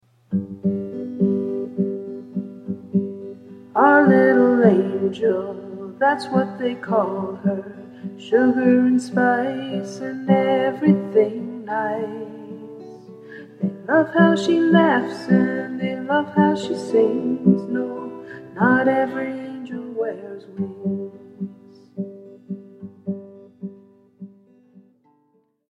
Sample from the Vocal MP3